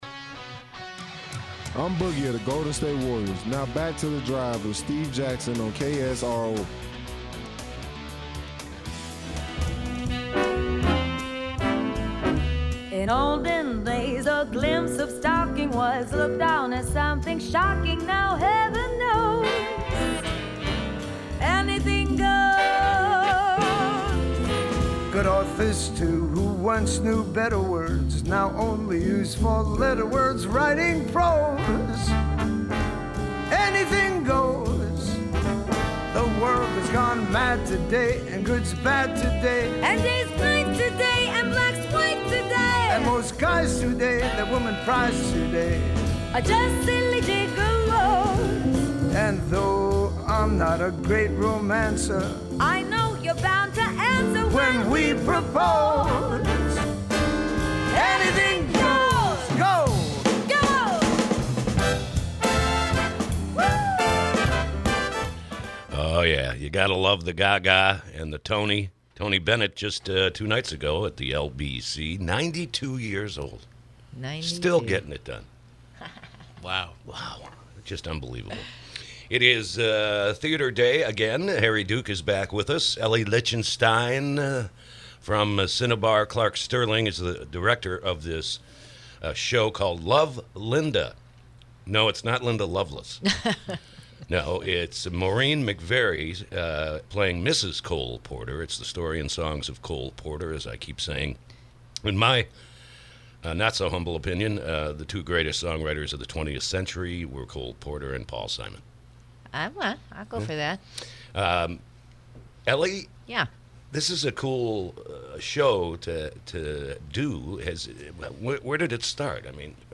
KSRO Interview: “Love, Linda”